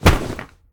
Pillow_chest_drop_01.ogg